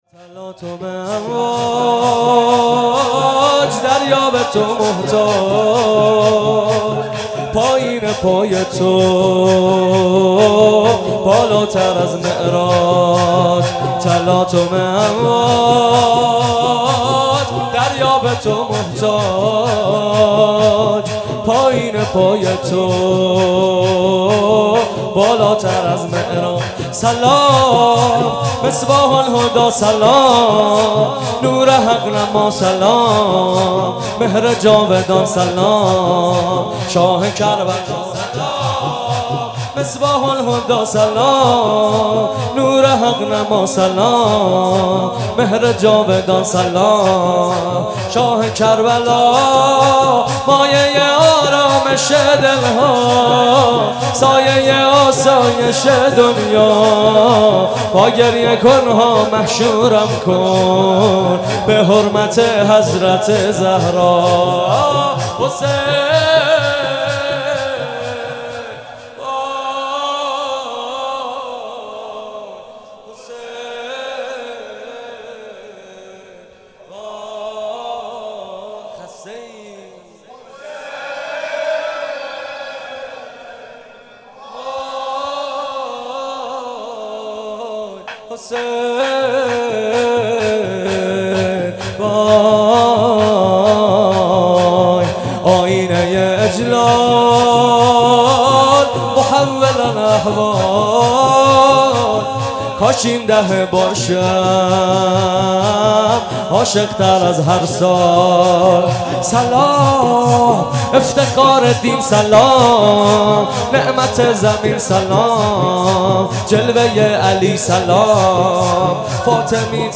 مراسم شب ۲۹ محرم ۱۳۹۷
دانلود شور